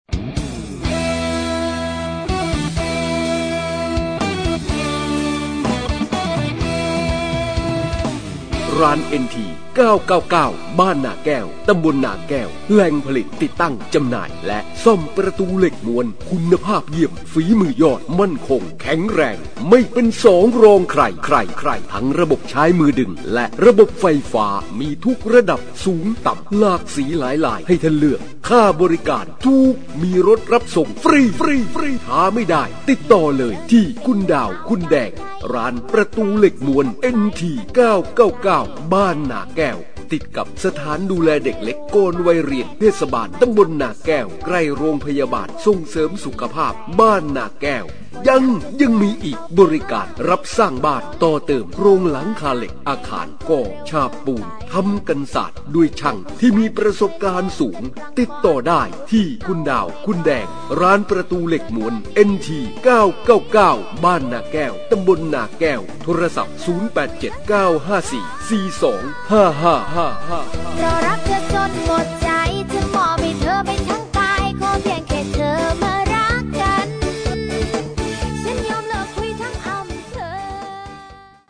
สปอตร้านเอ็นที 999